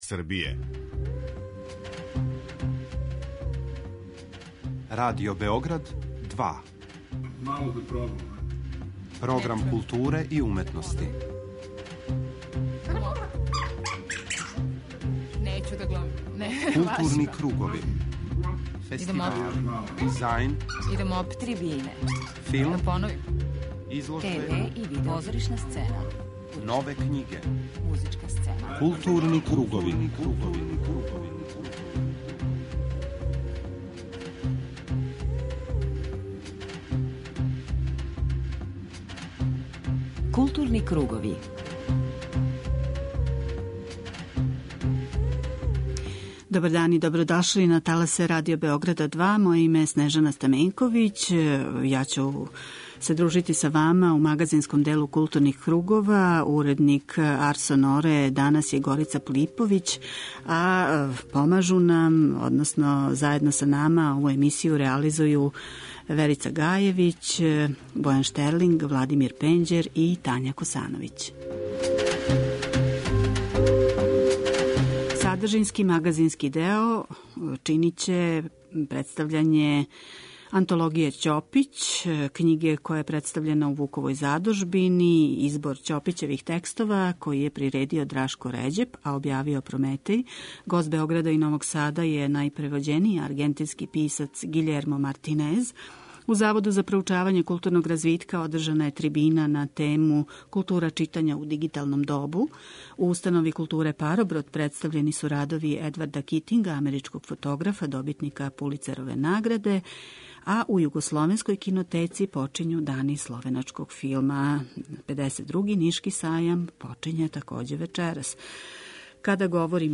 У магазинском делу данашњих 'Културних кругова' представићемо књигу 'Антологија Ћопић', коју је приредио Драшко Ређеп